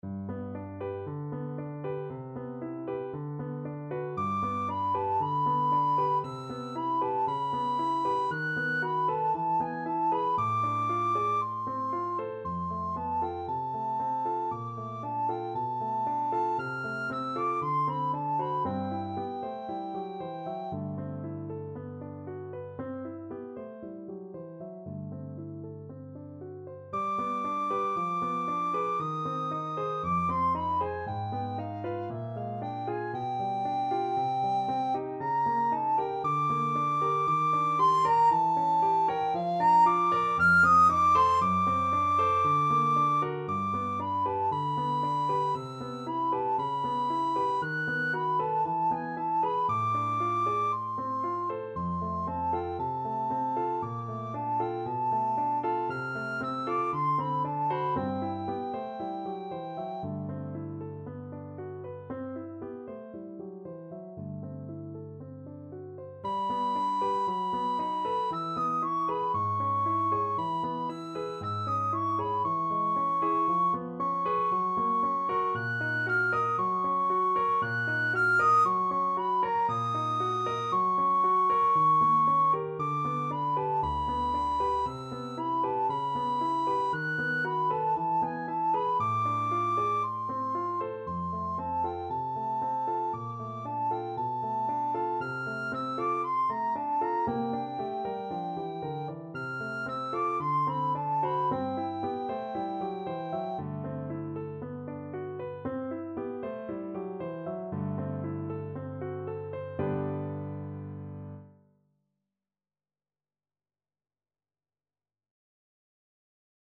Free Sheet music for Soprano (Descant) Recorder
Recorder
G major (Sounding Pitch) (View more G major Music for Recorder )
Allegro moderato =116 (View more music marked Allegro)
Classical (View more Classical Recorder Music)